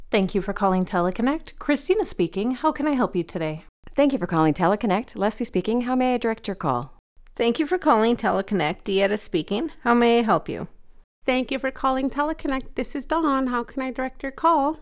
Our team’s neutral, North American accents ensure that every interaction feels local and genuine.
Thank-you-for-calling-Teleconnect.mp3